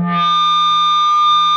PRS FBACK 3.wav